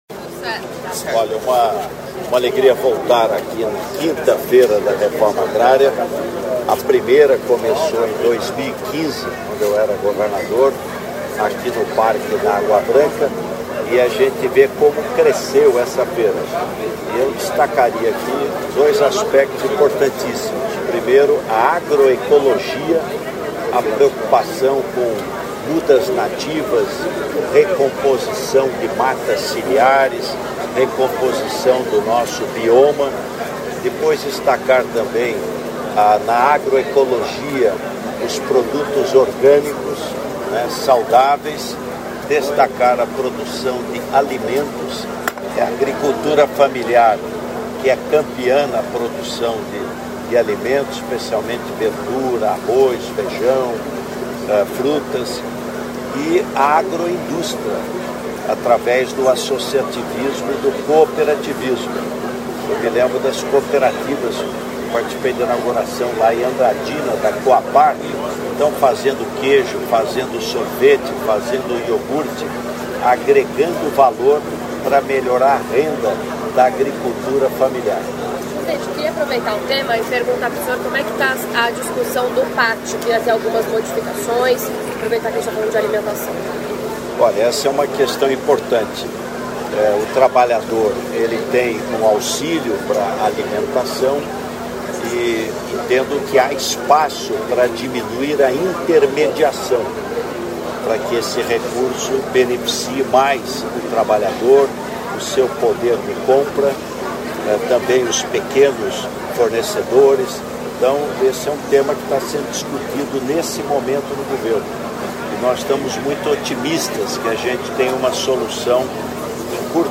Íntegra do discurso do presidente Luiz Inácio Lula da Silva no encontro com atletas dos Jogos Olímpicos de Paris 2024, nesta segunda-feira (26), no Palácio do Planalto em Brasília.